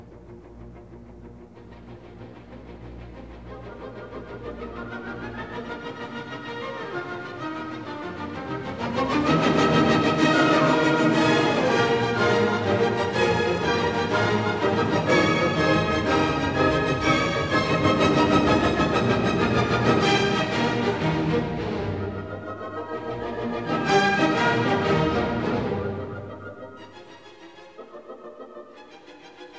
1960 stereo recording